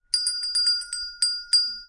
allinone " Bell 1
描述：响铃
Tag: 编钟